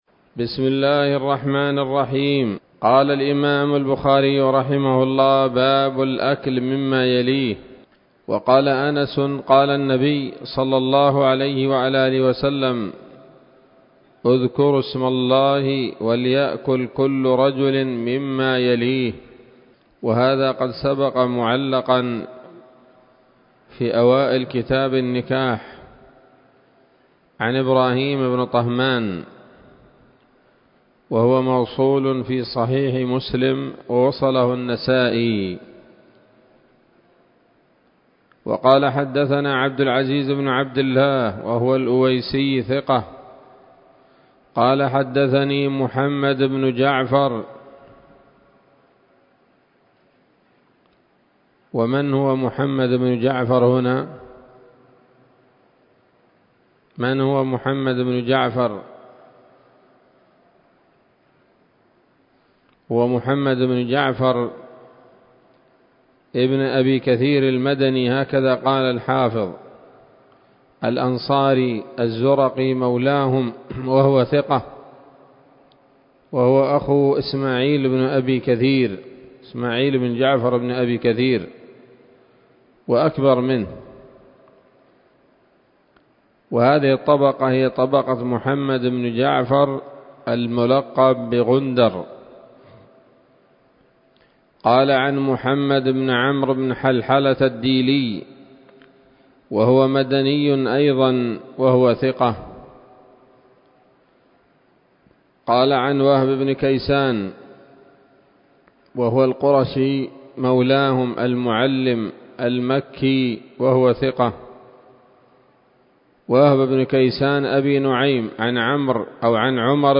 الدرس الثالث من كتاب الأطعمة من صحيح الإمام البخاري